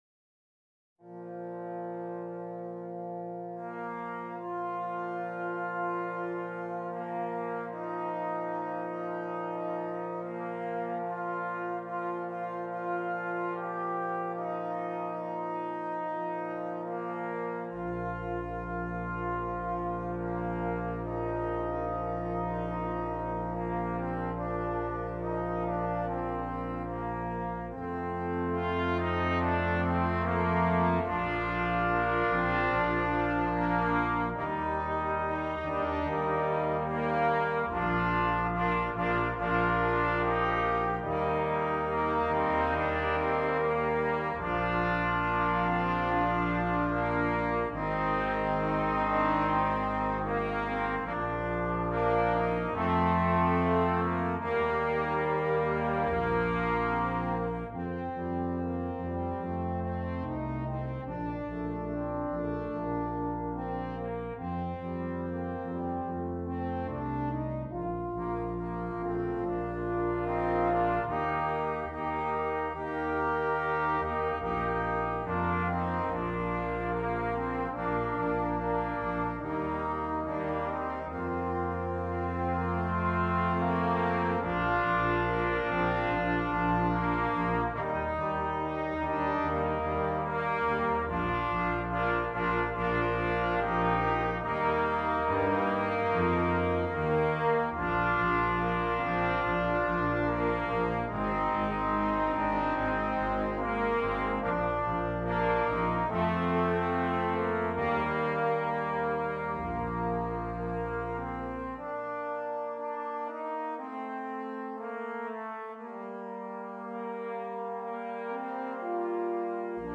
Brass Quintet